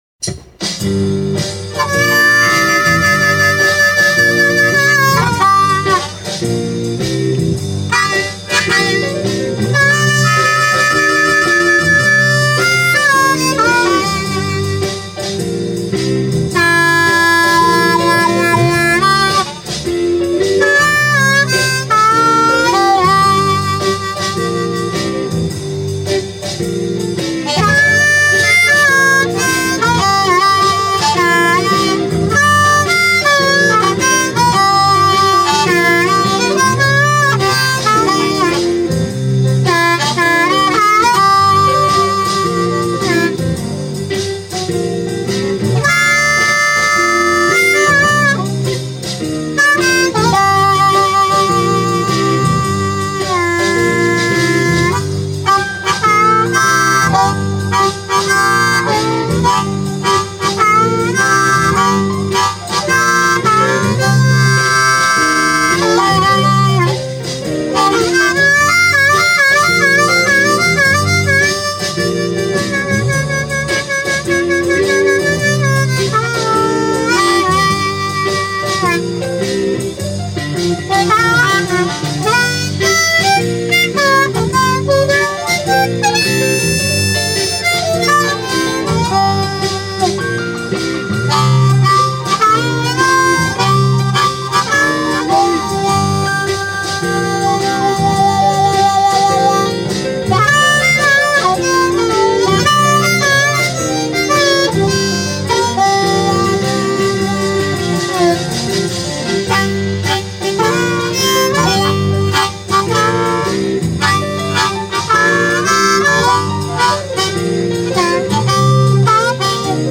Mundharmonika MP3 Hörbeispiele Download
Got the Blues.....